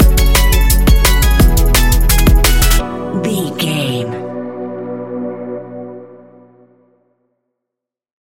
Ionian/Major
techno
trance
synths
synthwave
instrumentals